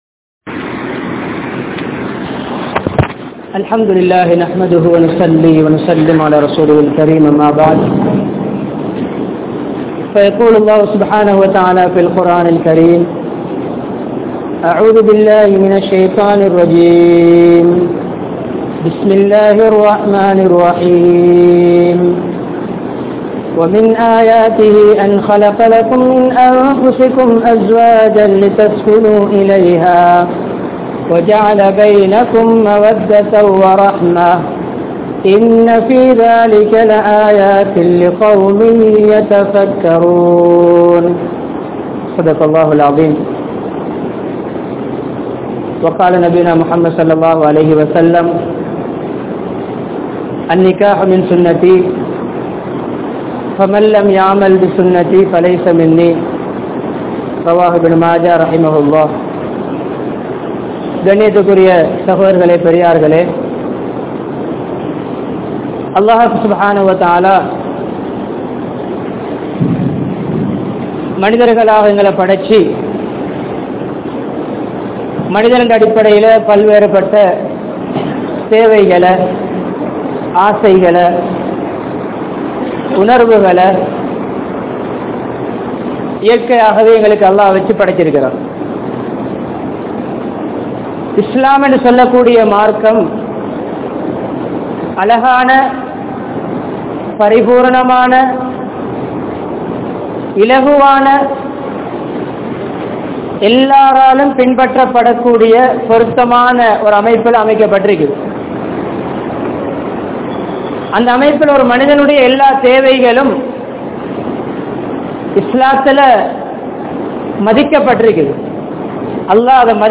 Illara Vaalkaium Media`vum (இல்லற வாழ்க்கையும் மீடியாவும்) | Audio Bayans | All Ceylon Muslim Youth Community | Addalaichenai
Kandauda Jumua Masjidh